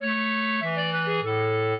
clarinet
minuet3-3.wav